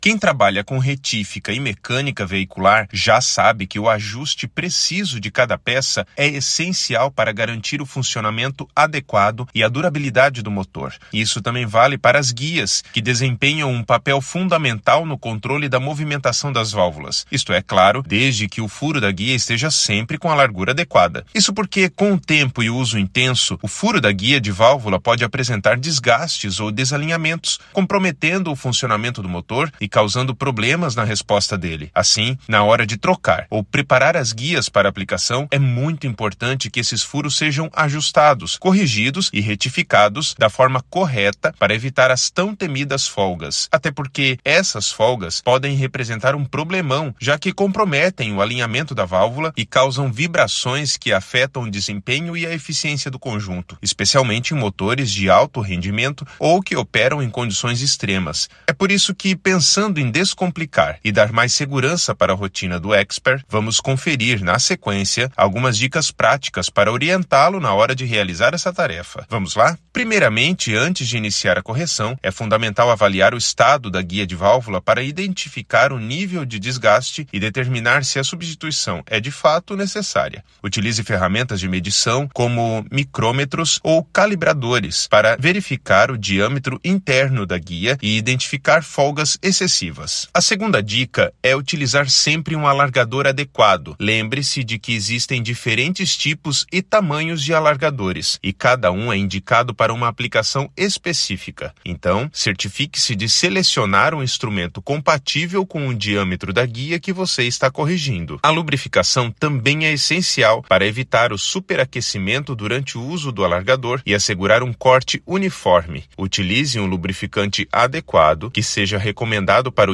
Narracao-01-alargador-de-sede-de-valvula.mp3